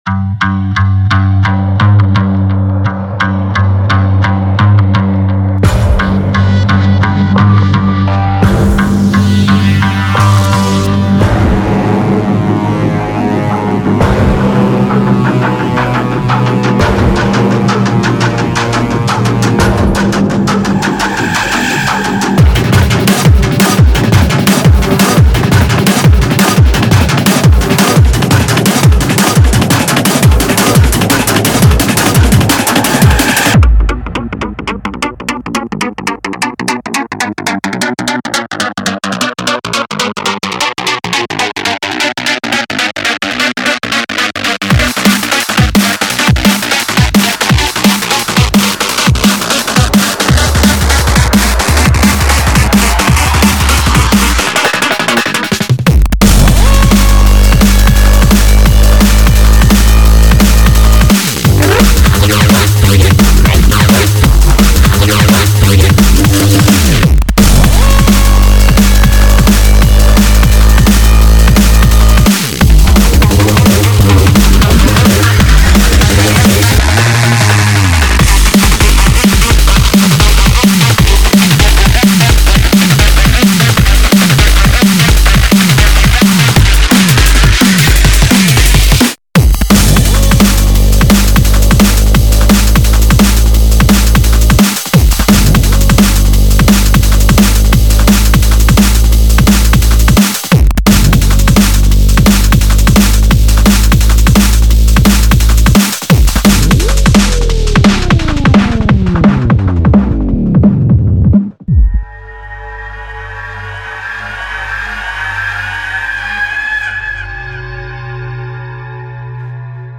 BPM172
MP3 QualityMusic Cut